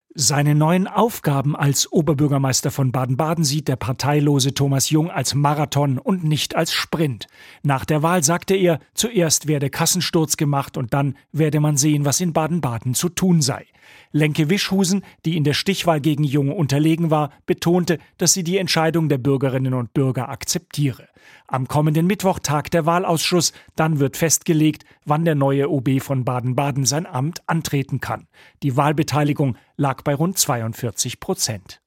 Der neue OB von Baden-Baden, Thomas Jung, direkt nach der Wahl